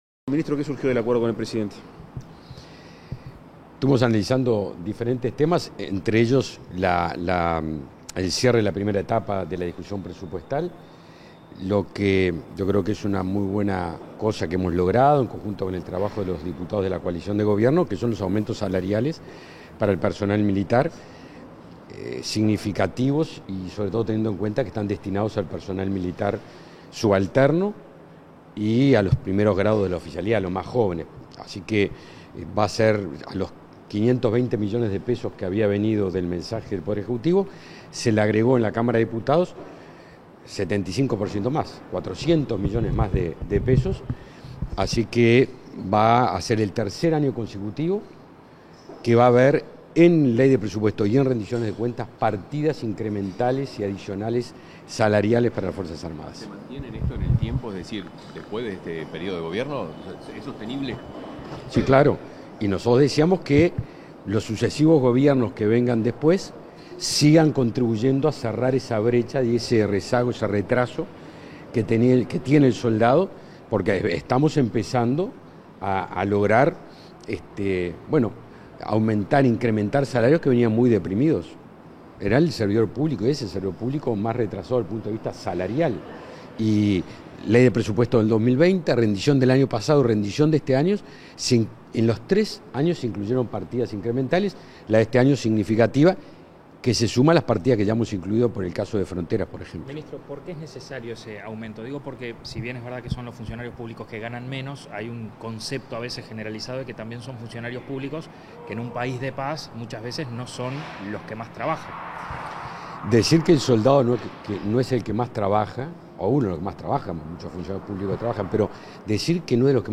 Declaraciones a la prensa del ministro de Defensa Nacional, Javier García